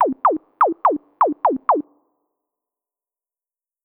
PERCUSSN009_DISCO_125_X_SC3.wav